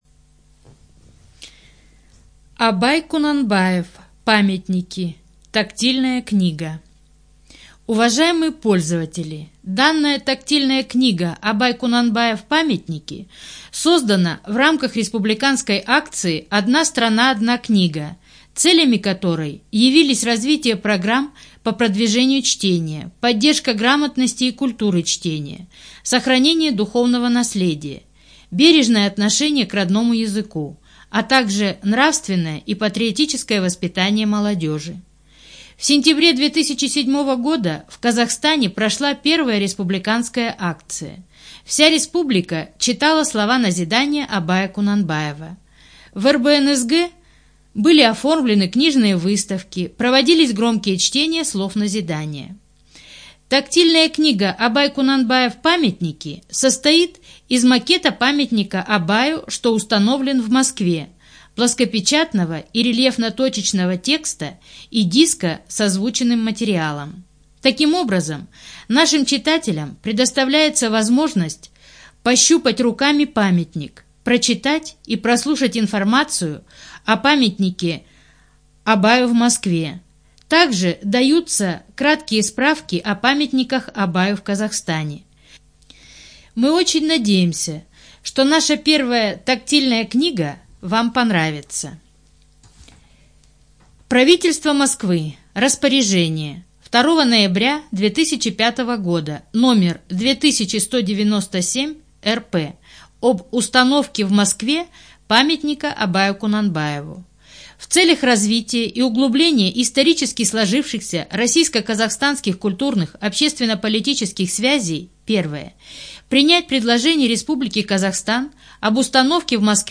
Студия звукозаписиКазахская республиканская библиотека для незрячих и слабовидящих граждан